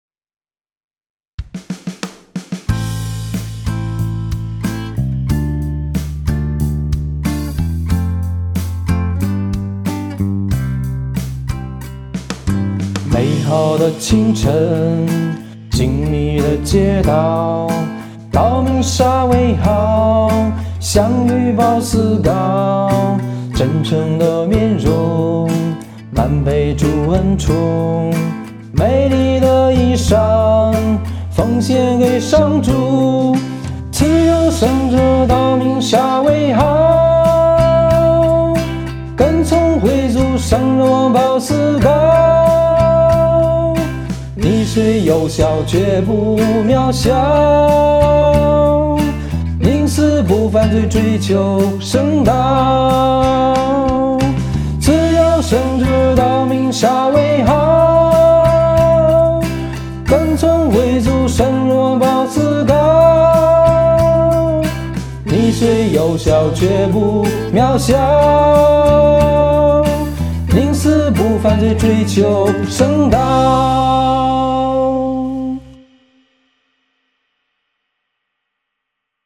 【原创圣歌】|《圣道明沙维豪》香港慈幼会修院生命之光参赛作品